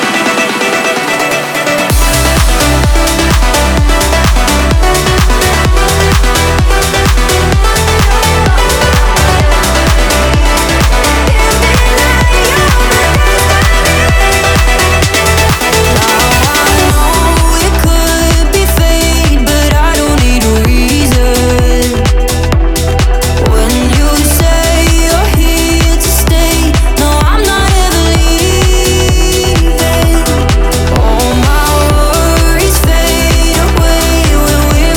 2025-10-17 Жанр: Танцевальные Длительность